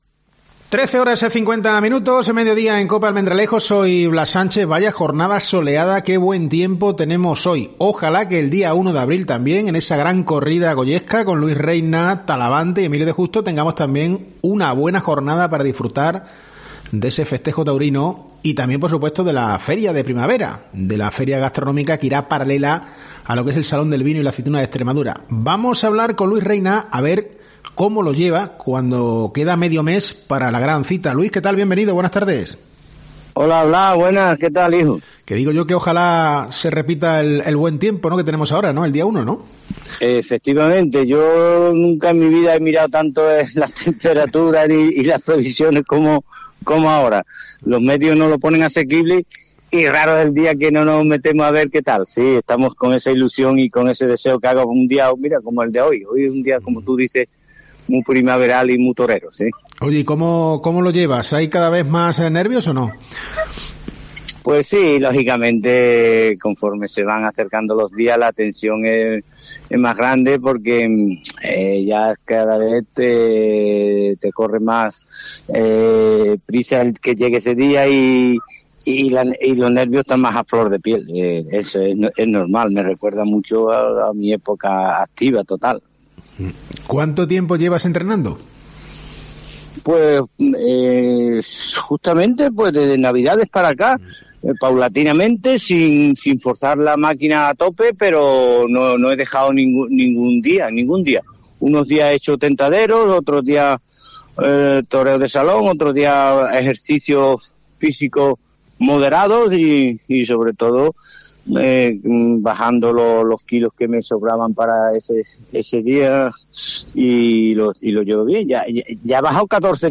Hemos hablado con él en COPE. Dice que va a llegar preparado a la cita y que en estas semanas está experimentando sensaciones que vivió hace 40 años.